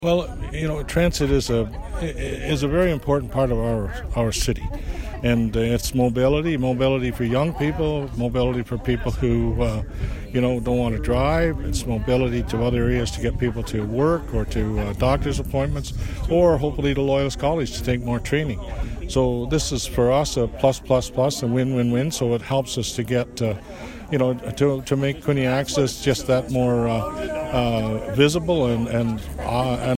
Jim Harrison, mayor of Quinte West says this will help connect the community.
Jim-Harrison-Quinte-Access-transit-expansion.mp3